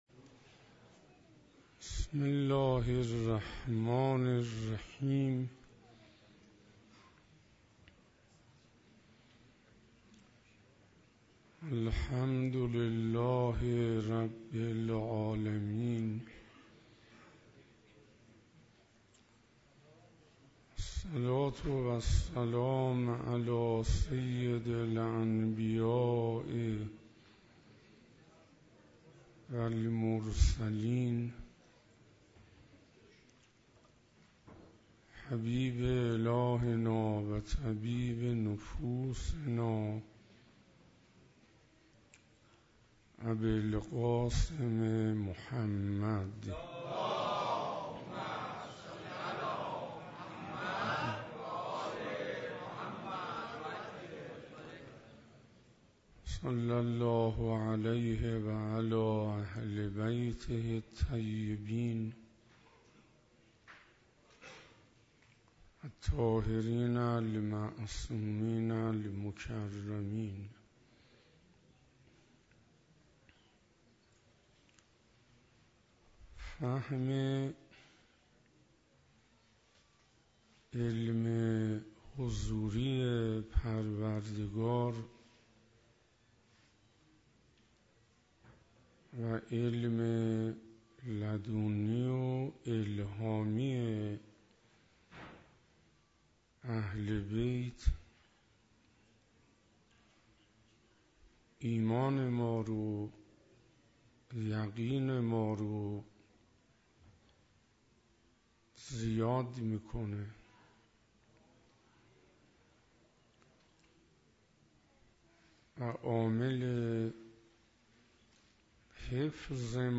فاطمیه 97 - مسجد جامع غدیر - شب سوم - معارف اسلامی